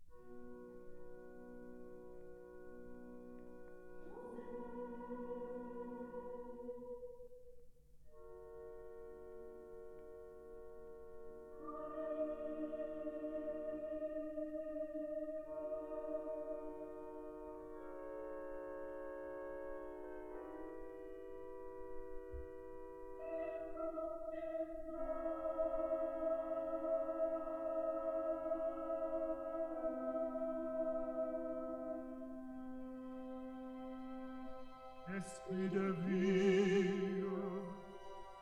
Scene 6: Choir of angels: Joseph! Marie!.
Stereo recording made in London